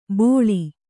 ♪ bōḷi